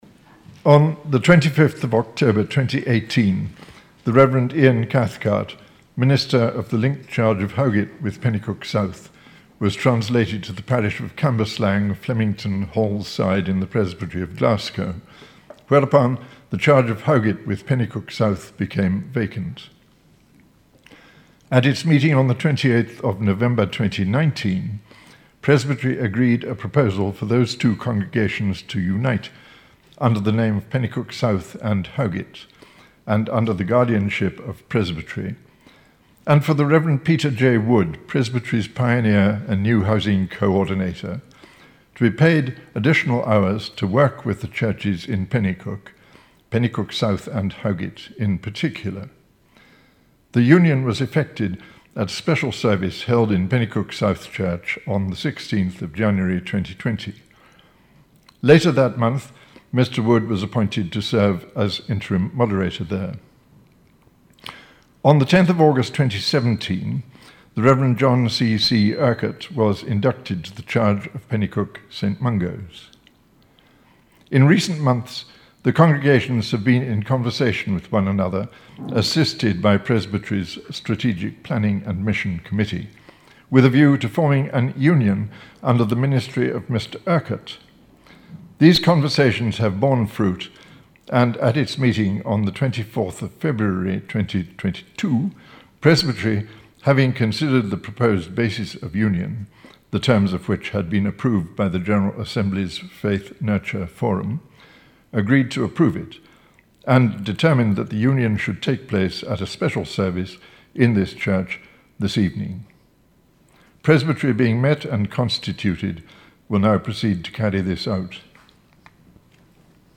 Penicuik Trinity Community Church was led by Presbytery on Thursday 7 April.